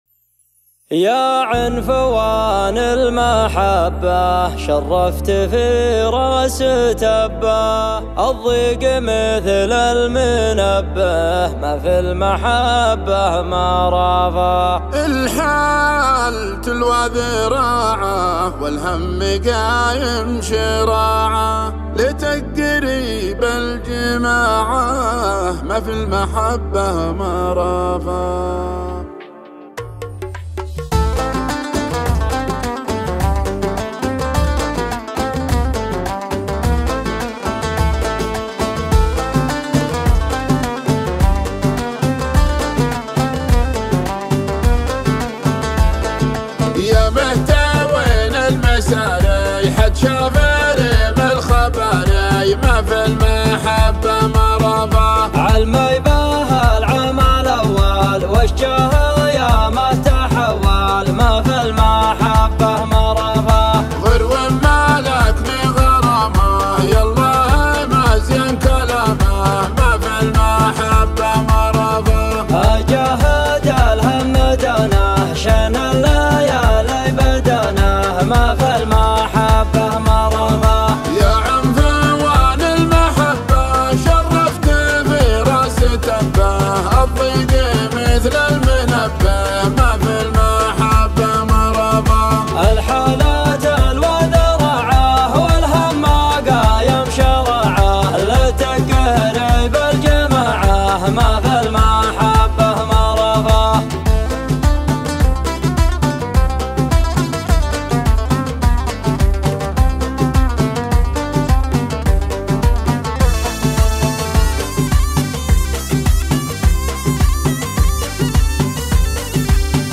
شيلات دويتو